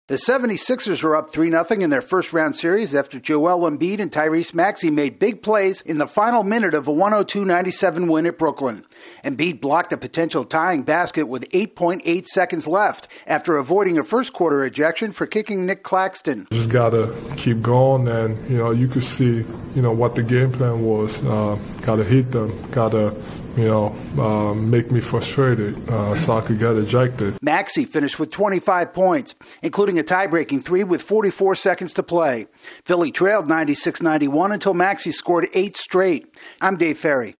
The 76ers rally late to beat the Nets for the third straight game. AP correspondent